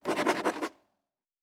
Writing 4.wav